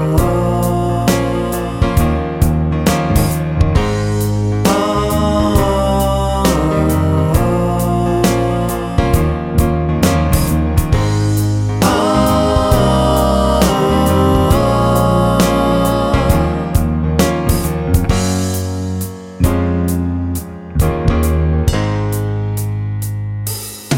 Two Semitones Down Soul / Motown 4:28 Buy £1.50